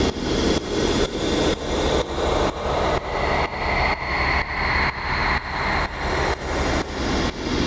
Index of /musicradar/sidechained-samples/125bpm